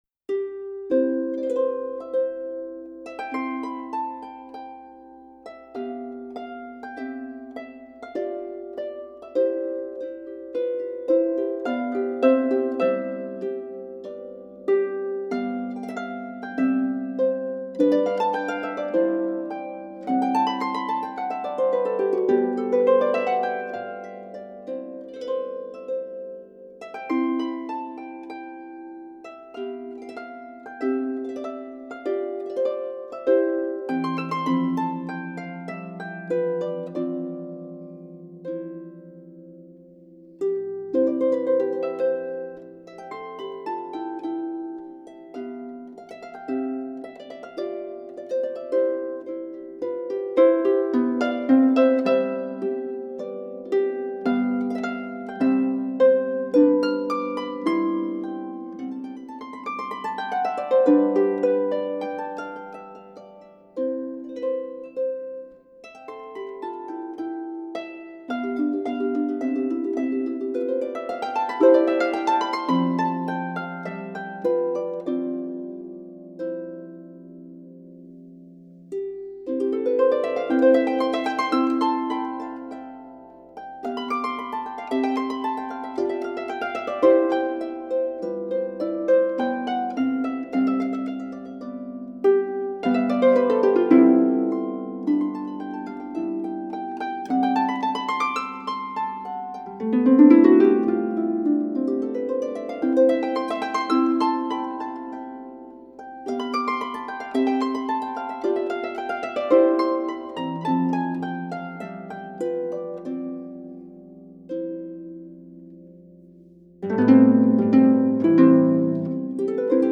Voicing: Harp w/ Audio